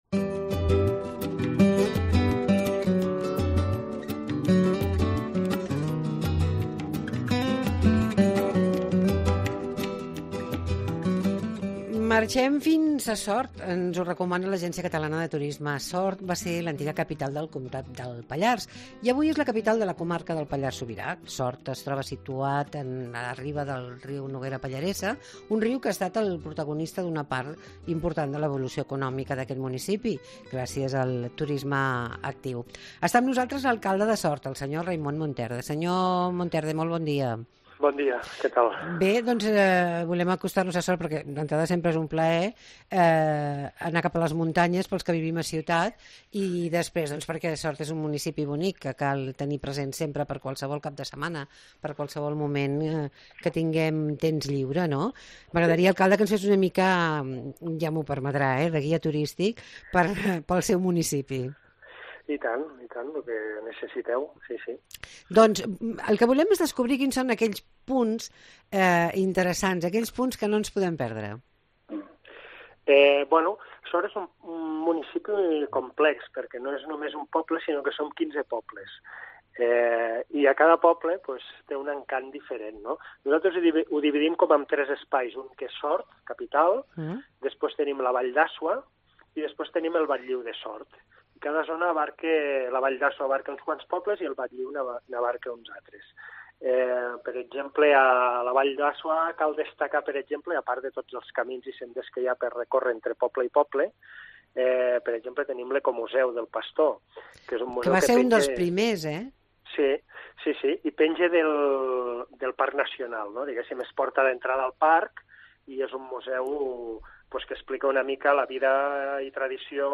Aquesta setmana l'Agència Catalana de Turisme ens proposa acostar-nos a Sort. Allà parlem amb el seu alcalde, Raimon Monterde de propostes de tardor